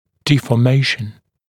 [ˌdiːfɔː’meɪʃn][ˌди:фо:’мэйшн]деформация, деформирование